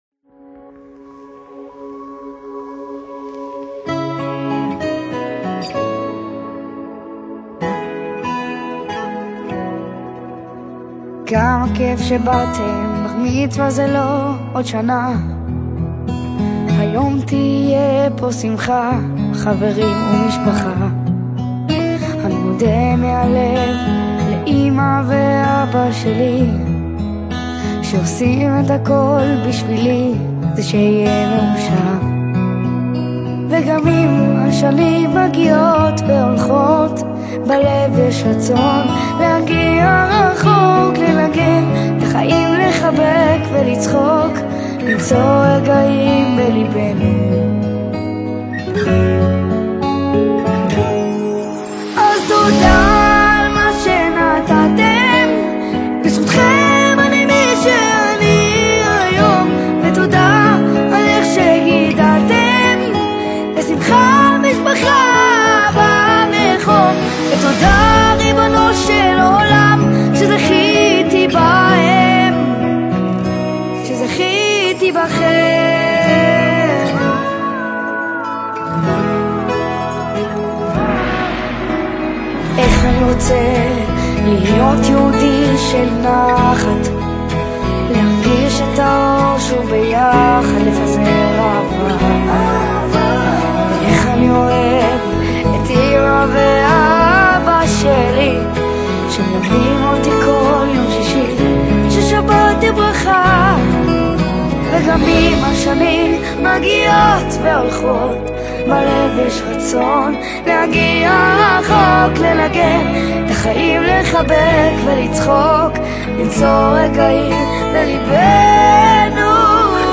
שיר תפילה אישי ועמוק